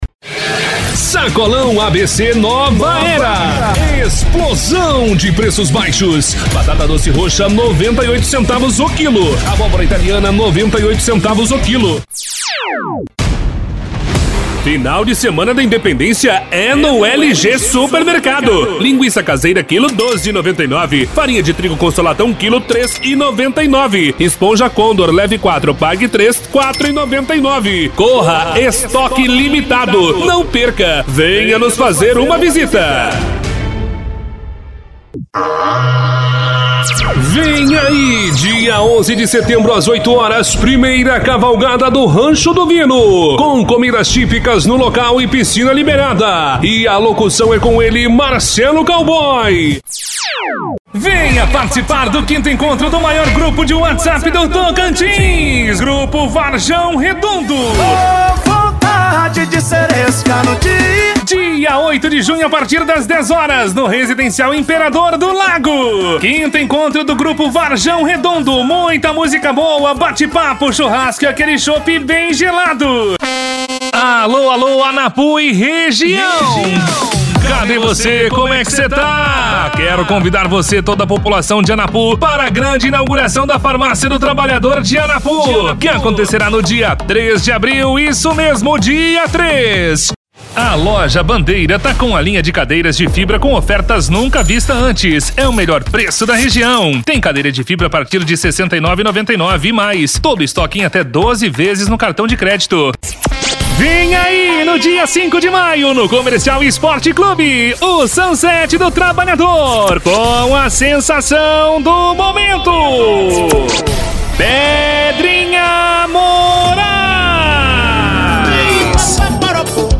Spot Comercial
Vinhetas
Padrão
Impacto
Animada
Caricata